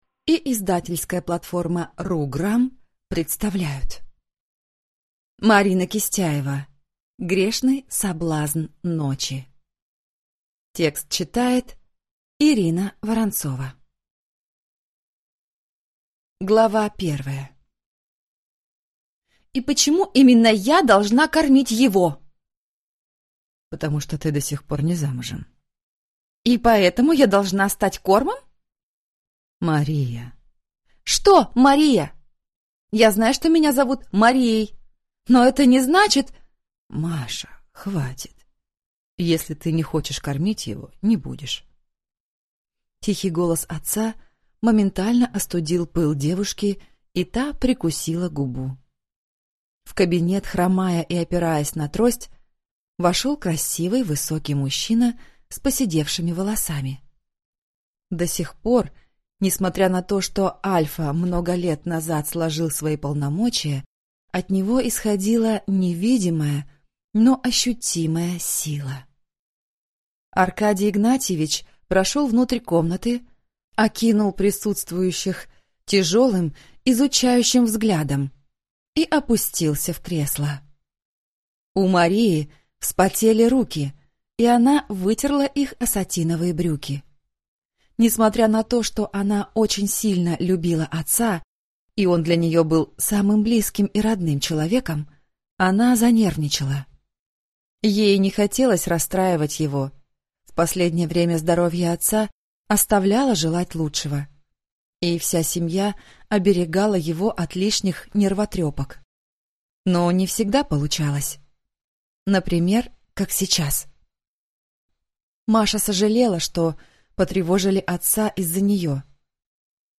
Аудиокнига Грешный соблазн ночи | Библиотека аудиокниг